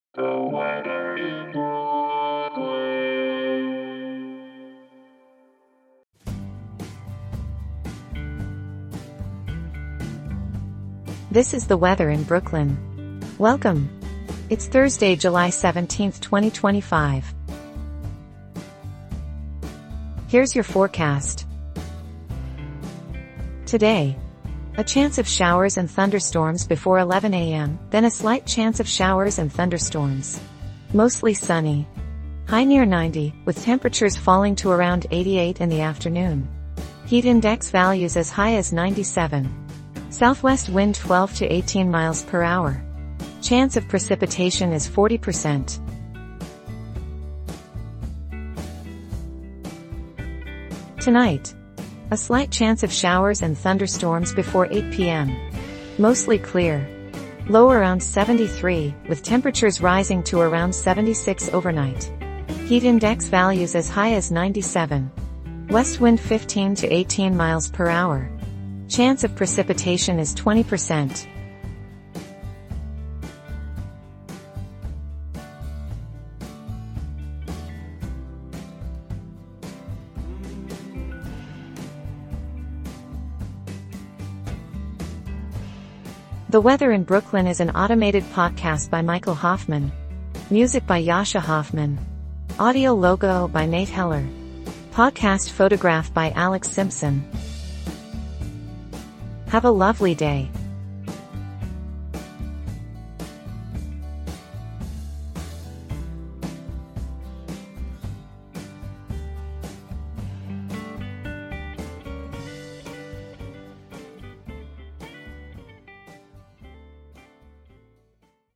and is generated automatically.